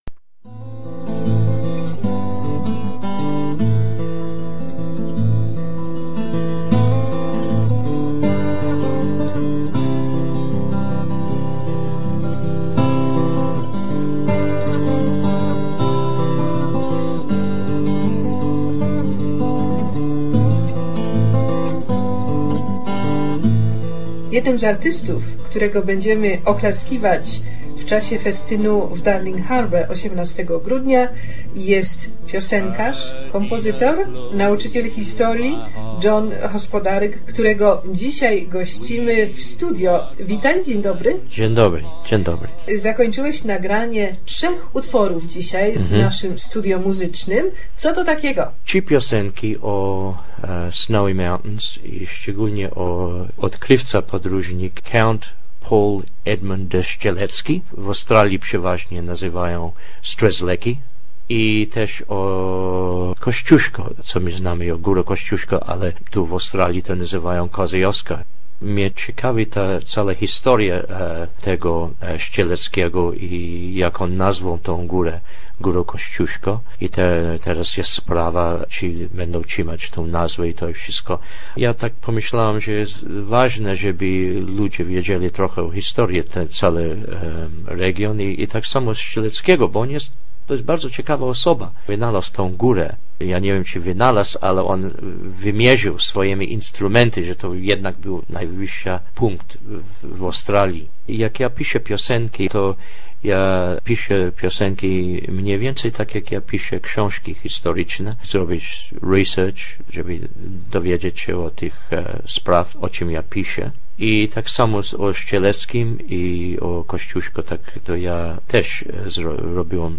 Tu archiwalny wywiad.